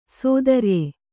♪ sōdarue